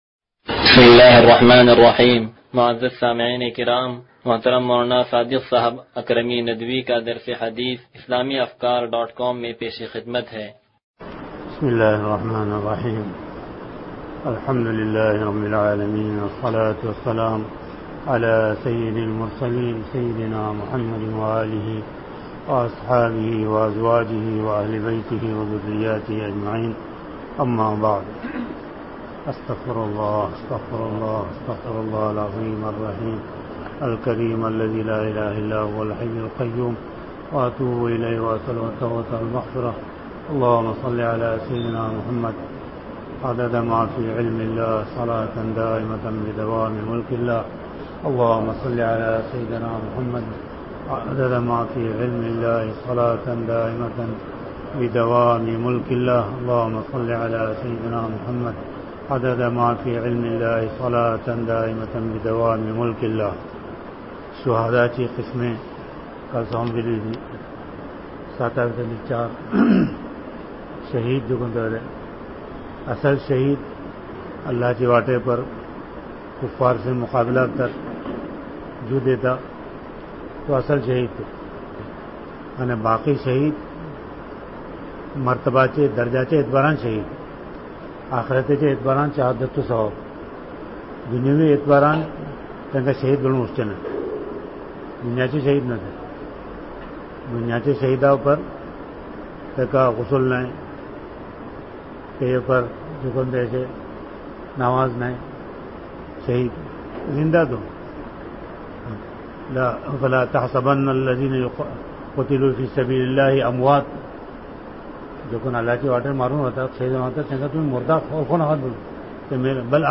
درس حدیث نمبر 0198
تنظیم مسجد